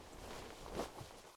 action_open_inventory_1.ogg